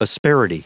Prononciation du mot asperity en anglais (fichier audio)
Prononciation du mot : asperity